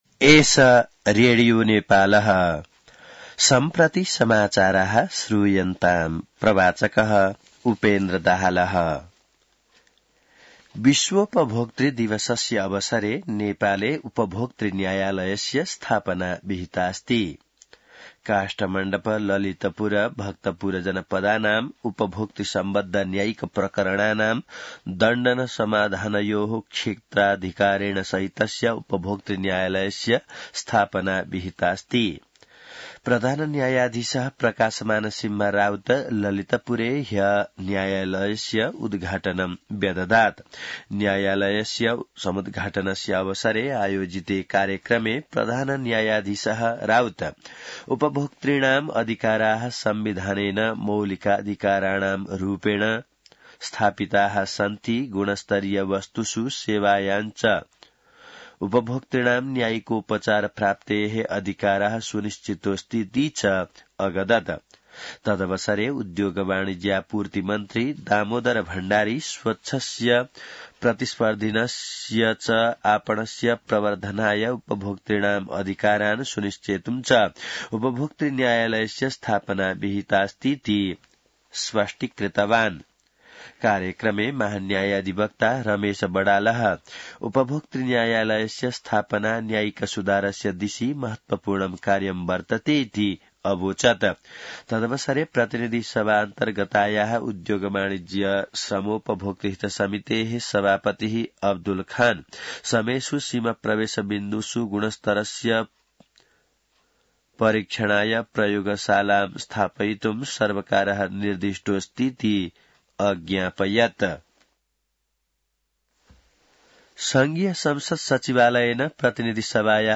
संस्कृत समाचार : ३ चैत , २०८१